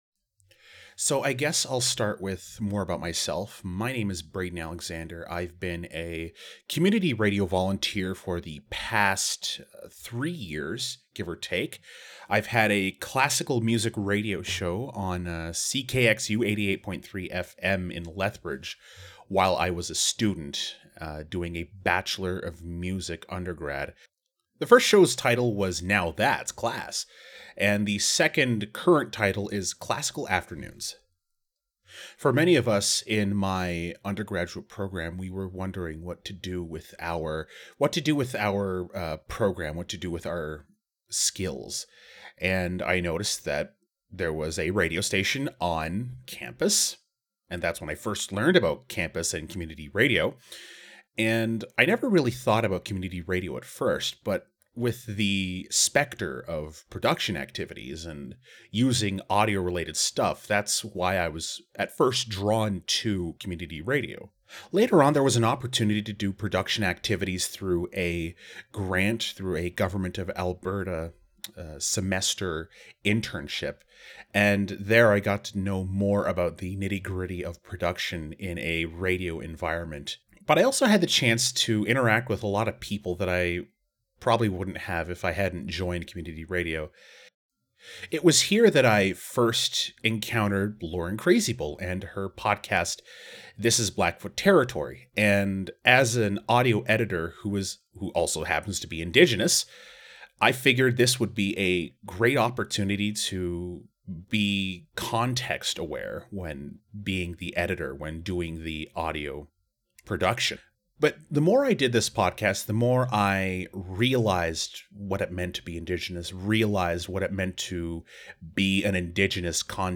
Type: Commentary
320kbps Stereo